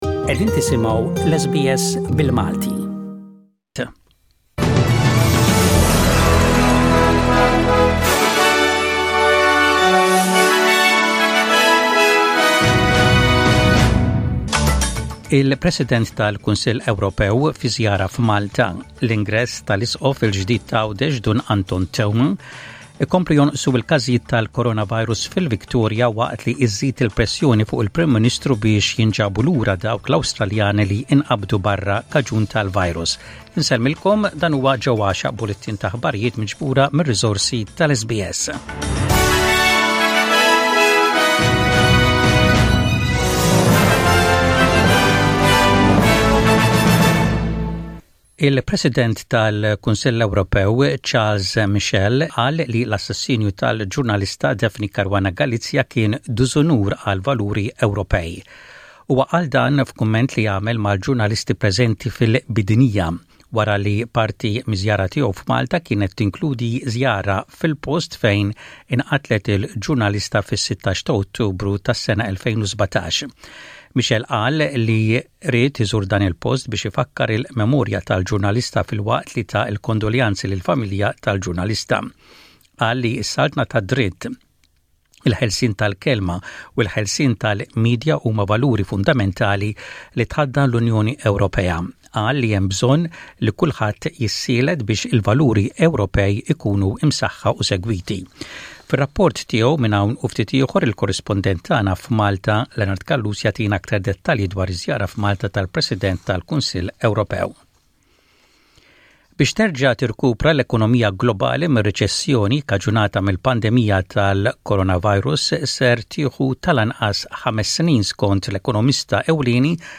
SBS Radio | Maltese News: 18/09/20